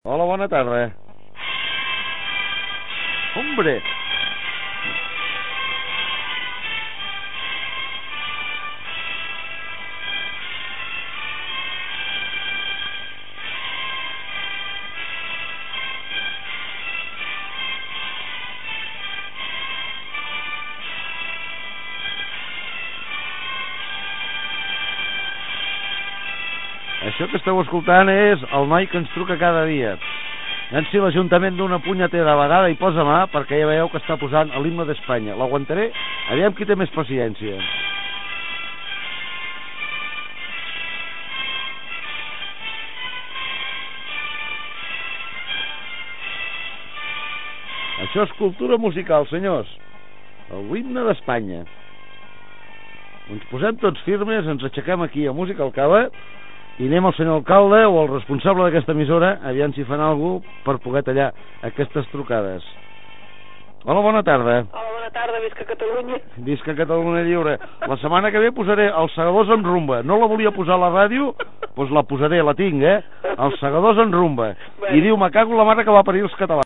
Trucada d'una persona que posa l'himne d'Espanya, com en dies anteriors, i inici de la següent trucada
FM